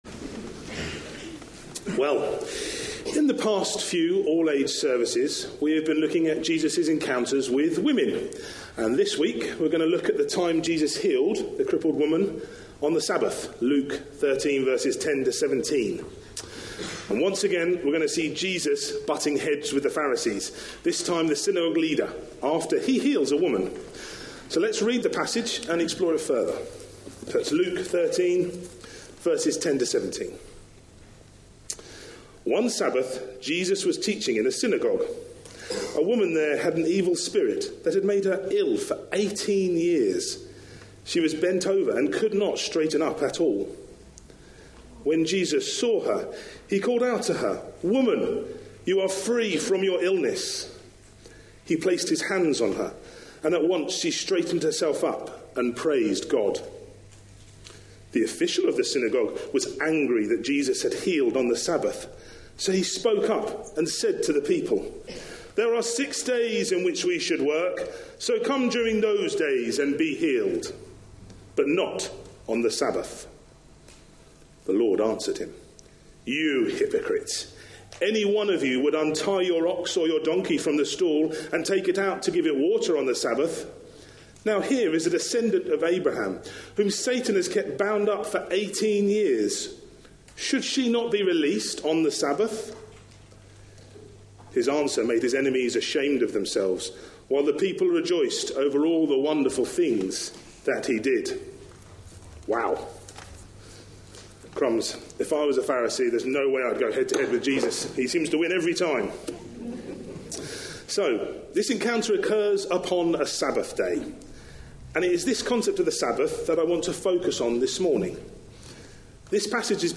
A sermon preached on 18th March, 2018.
(As the talk was in an all-age service, it included dramatic asistance from some younger members of the congregation.)